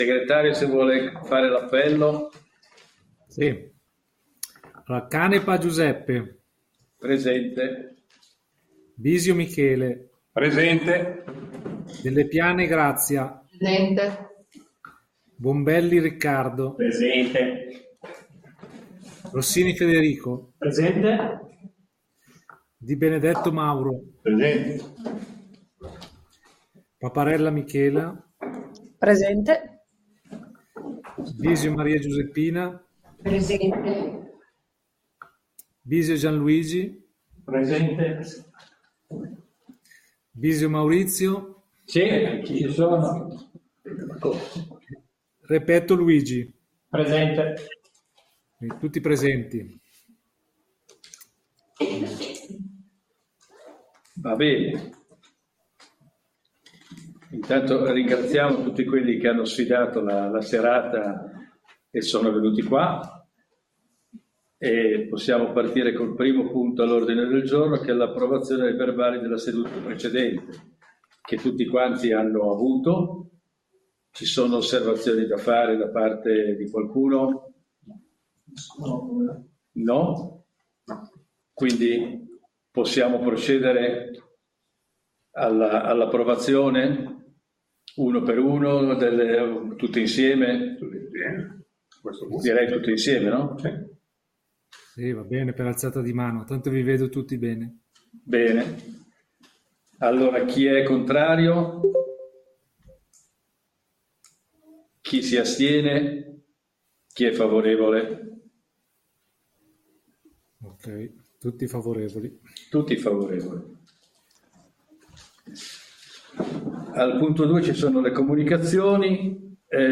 Seduta del Consiglio Comunale del 26/09/2024